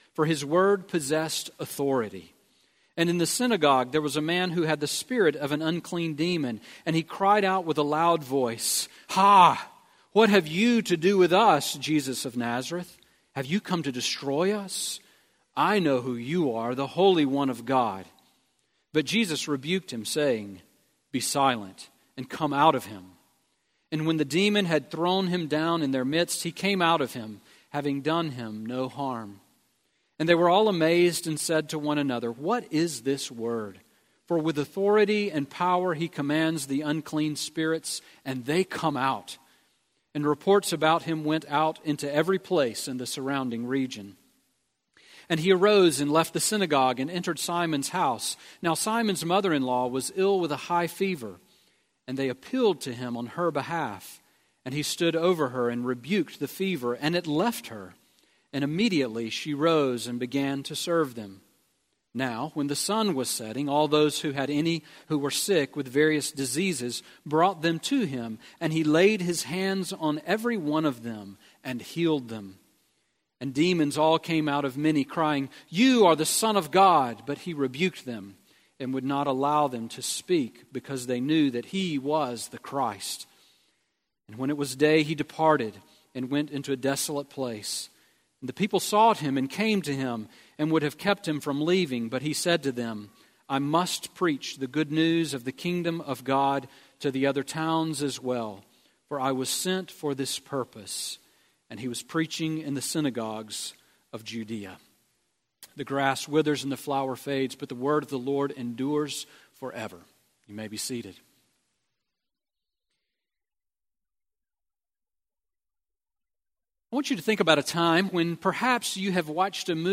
Sermon on Luke 4:31-44 from February 19